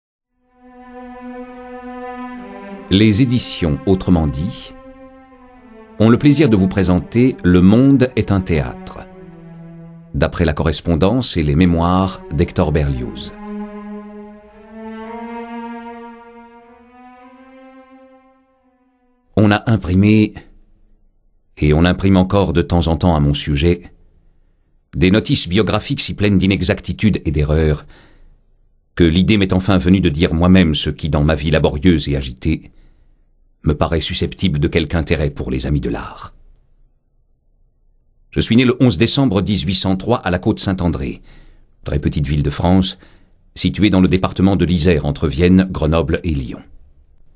Le livre audio